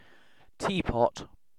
Ääntäminen
IPA : /ˈtiː.ˌpɒt/